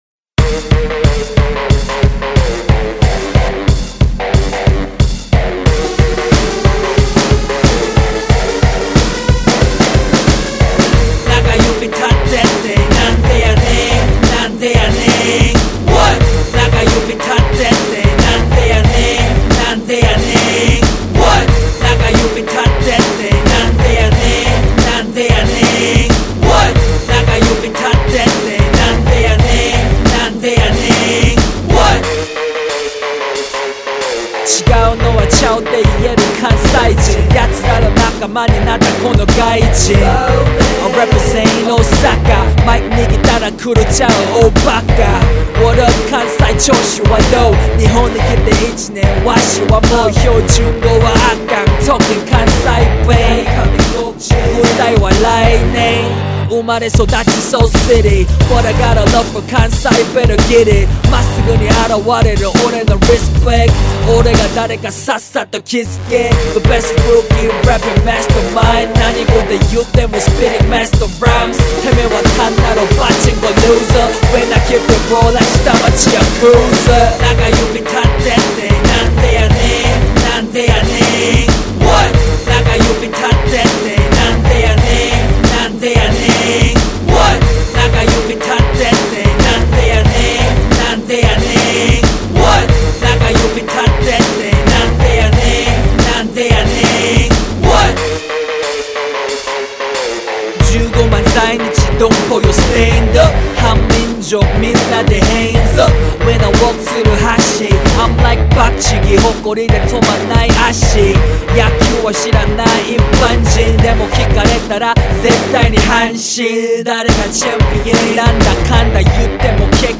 • [REMIX.]
실은 저도 랩을 합니다 ㅠㅠ 부끄러워서 올리거나 하지는 못했지만요 ㅠㅠ